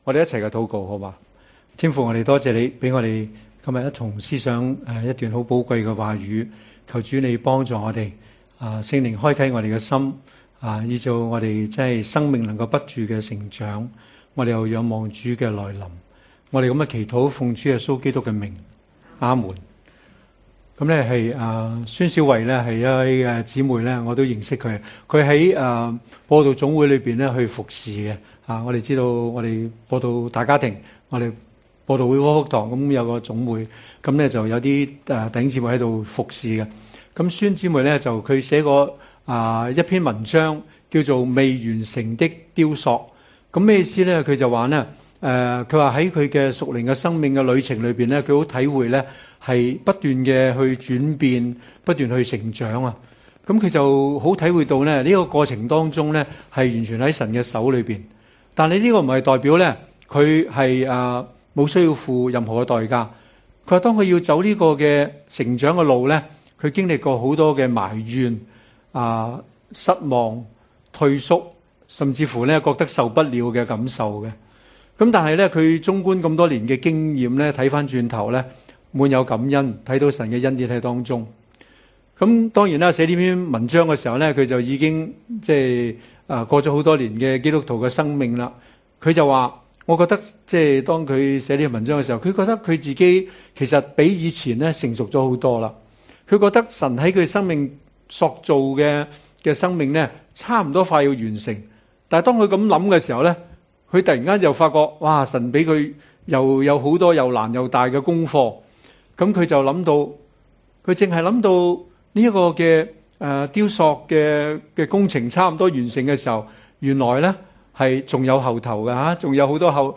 場所：主日崇拜